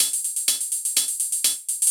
UHH_ElectroHatB_125-03.wav